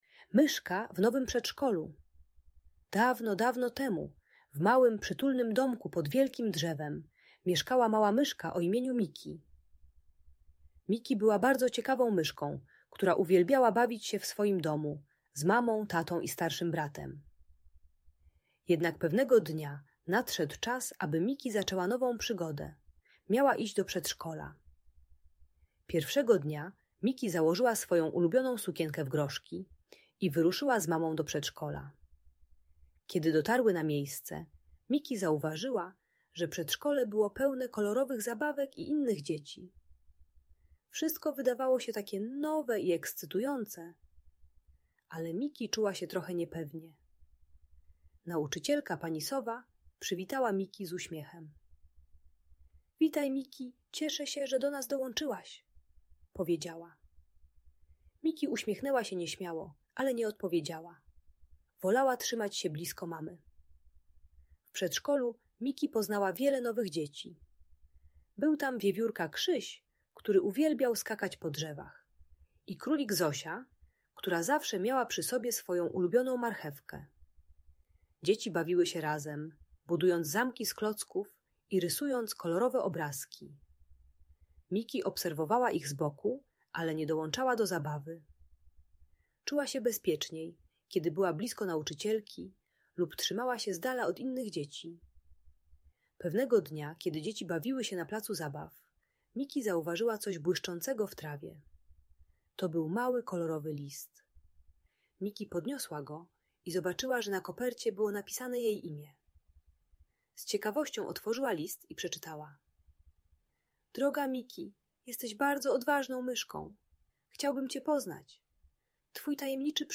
Opowieść o Myszce Miki w Nowym Przedszkolu - Audiobajka